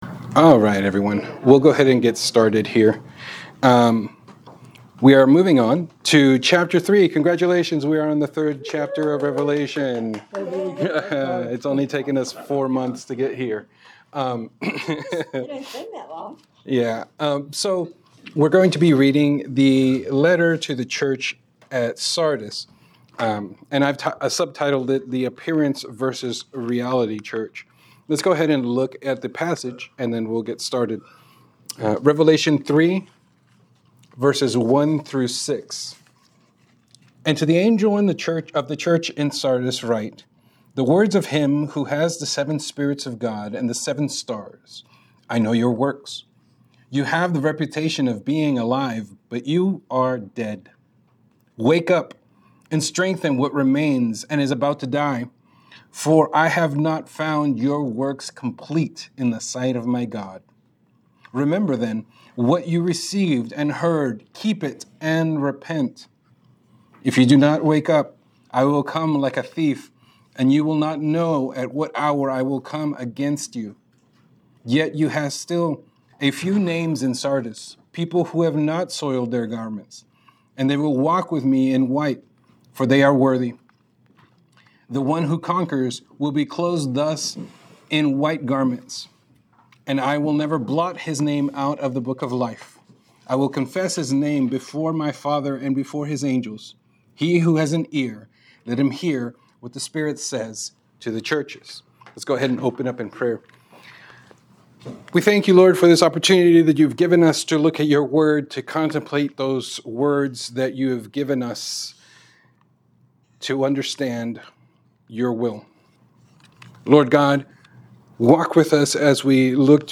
Revelation 3:1-6 Class Recording